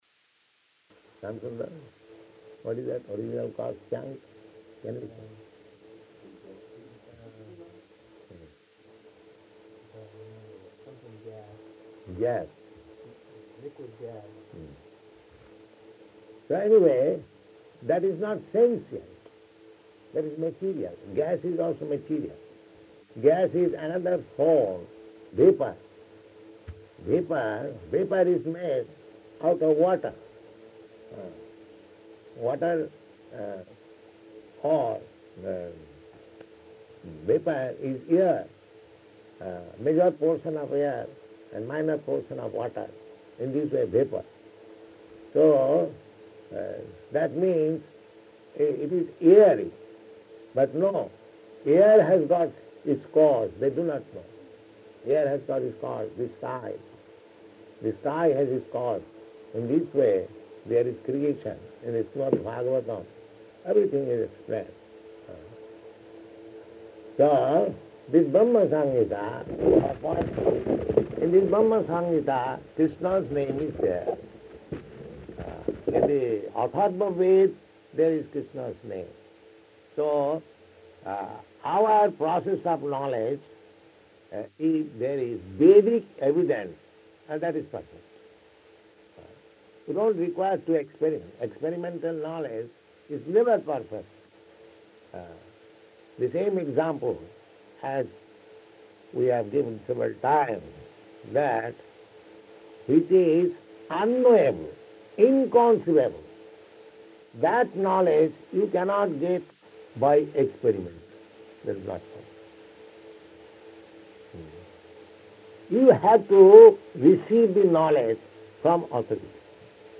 Janmāṣṭamī Morning Lecture [partially recorded]
Location: London